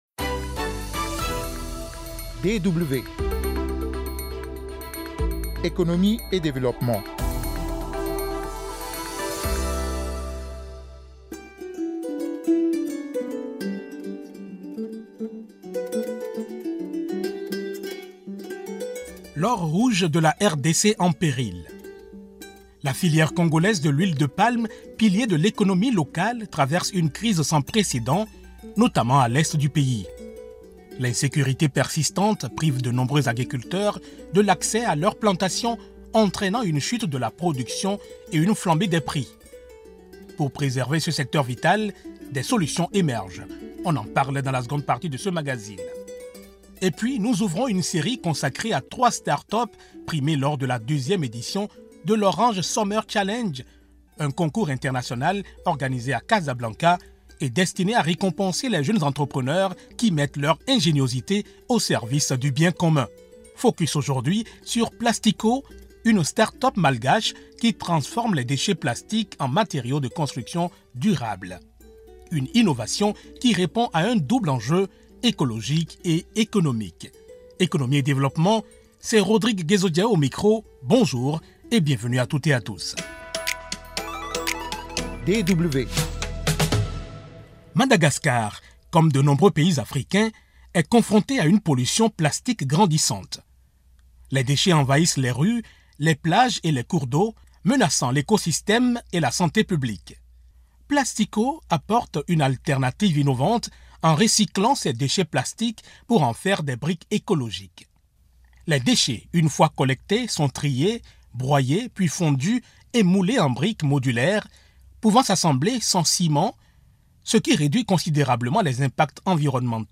Des analyses, des interviews et des reportages pour comprendre les évolutions actuelles, en Afrique et ailleurs.